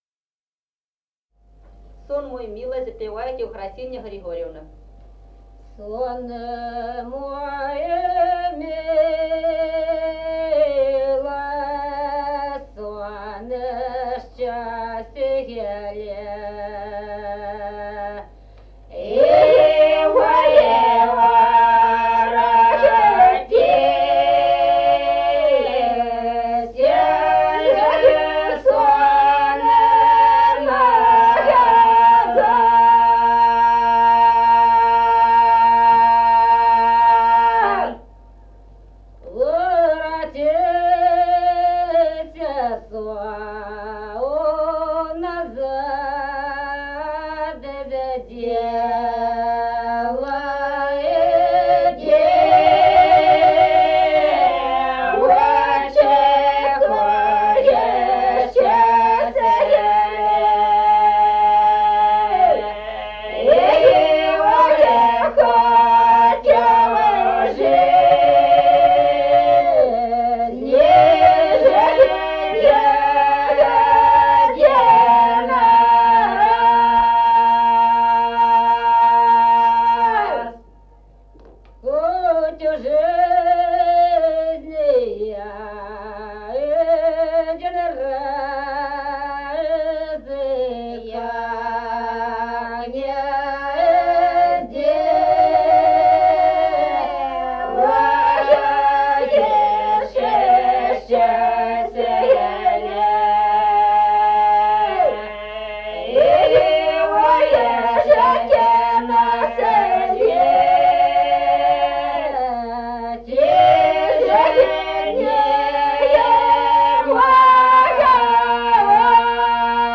Народные песни Стародубского района «Сон мой милый», лирическая.
с. Остроглядово.